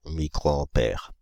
Ääntäminen
Paris: IPA: [mi.kʁo.ɑ̃.pɛʁ]